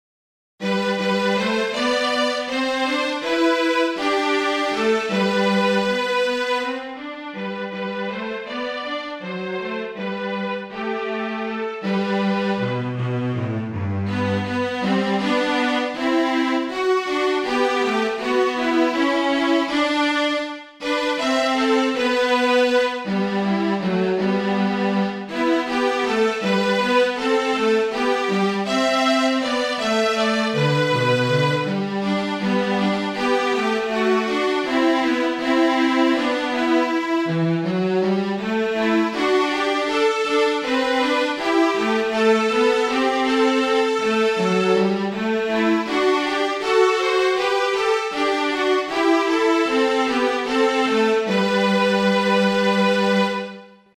for 2 Viols, Treble+Bass (or Tenor),